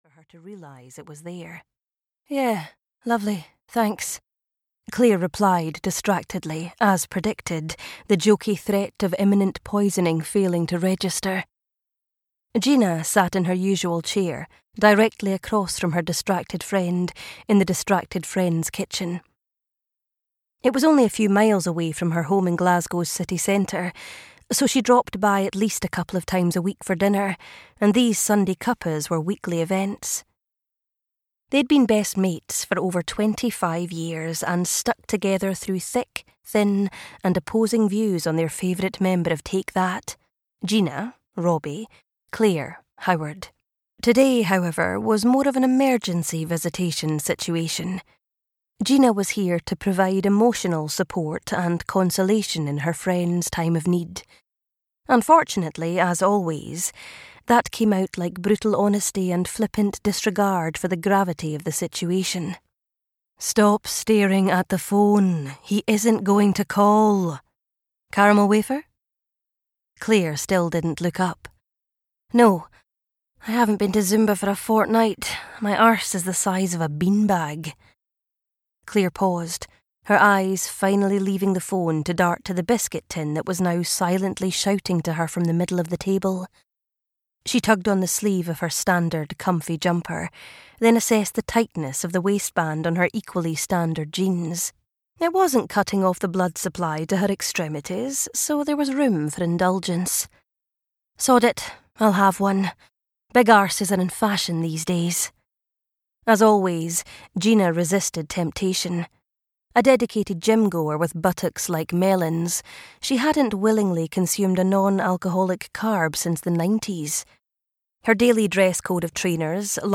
This is Me (EN) audiokniha
Ukázka z knihy